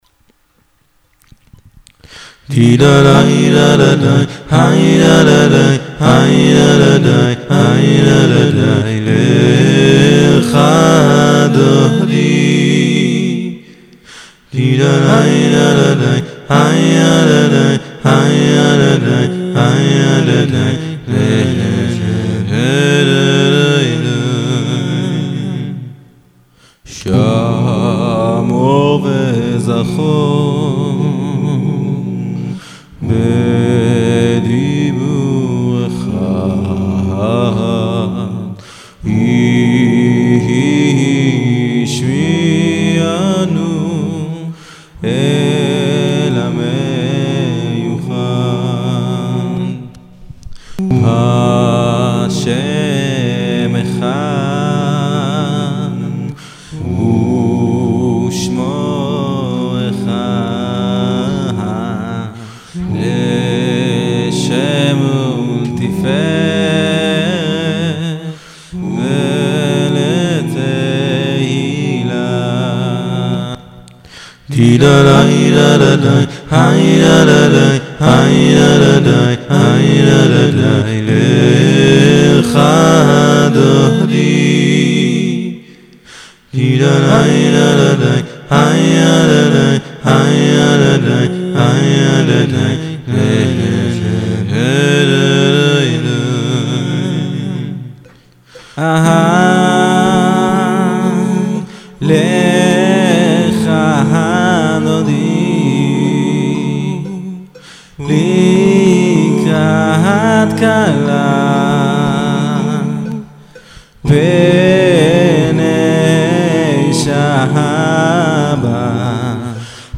מעט דיכאוני....( טוב, בגלל שאין מוזיקה..):